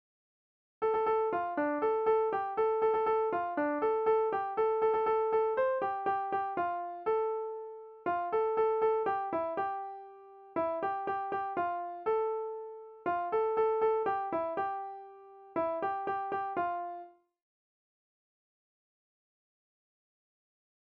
Île-d'Yeu (L')
Fonction d'après l'analyste gestuel : à marcher
Genre énumérative